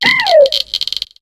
Cri d'Anchwatt dans Pokémon HOME.